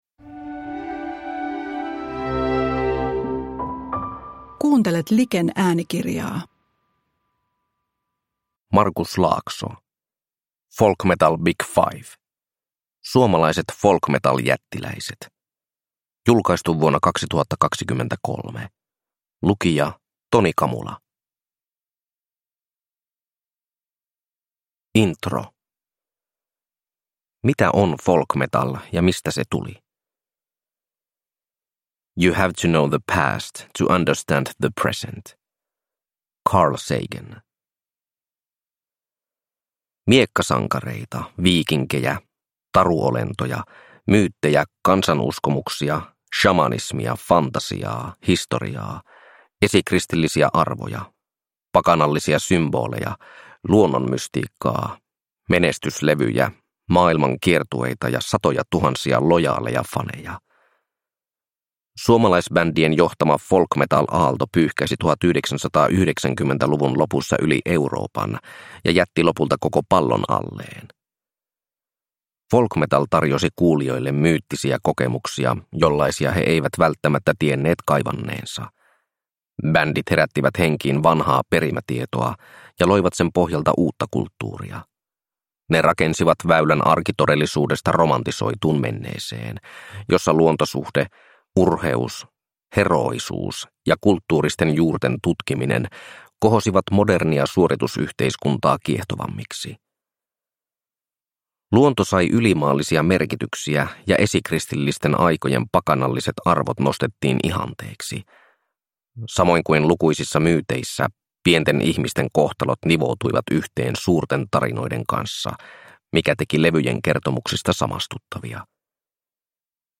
Folk Metal Big 5 – Ljudbok